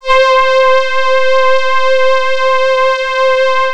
Strings (1).wav